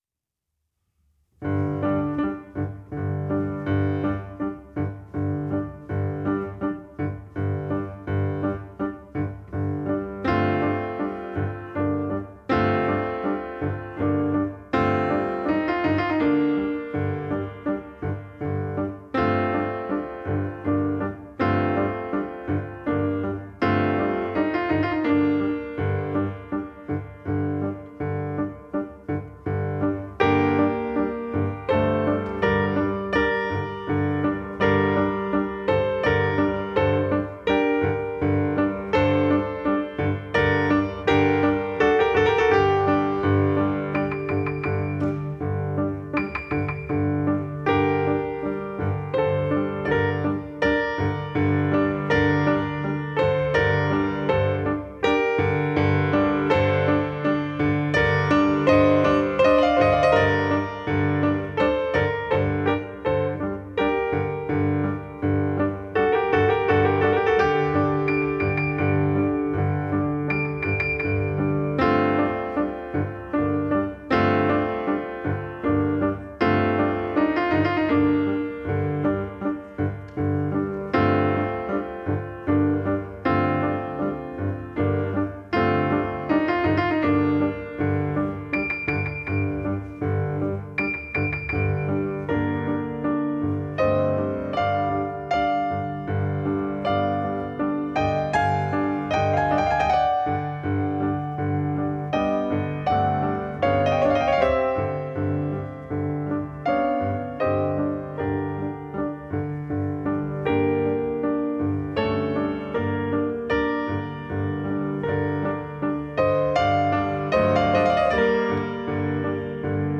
Two pianos
a live recording of a two piano recital
The combined sound of two concert grand pianos requires careful planning.